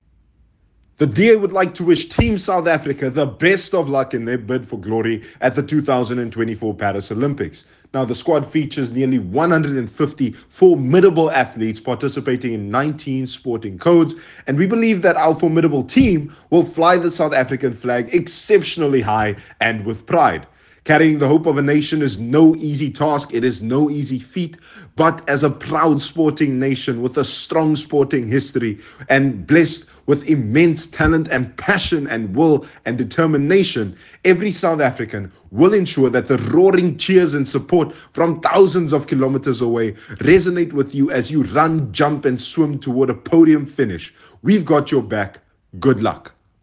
soundbite by Liam Jacobs MP